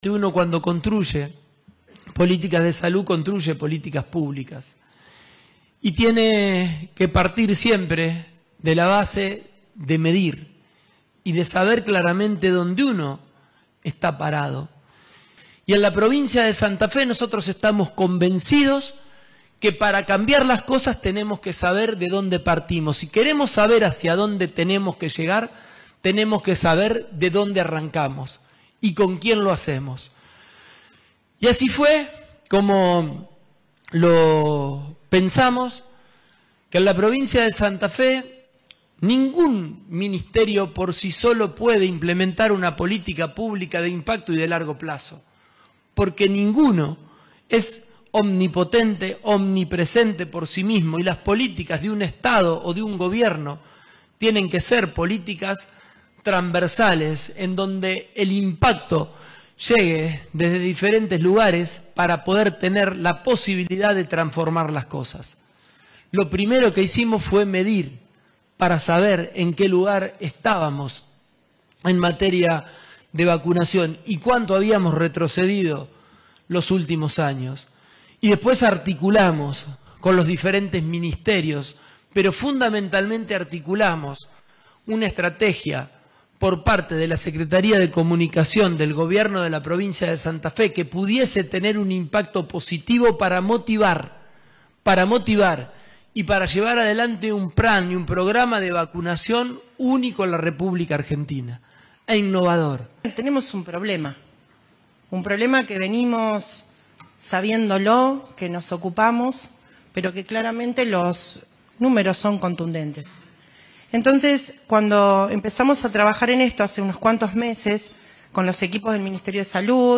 Durante el acto, realizado en la Sala Walsh de la sede de Gobierno, Pullaro subrayó que “ningún ministerio puede implementar por sí solo una política pública de impacto y a largo plazo”, y que las acciones sanitarias requieren articulación efectiva entre áreas.